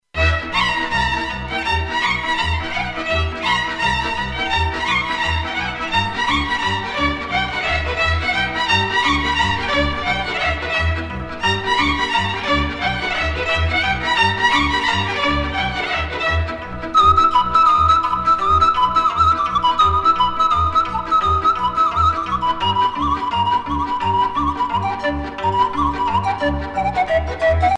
danse : sîrba (Roumanie)
Pièce musicale éditée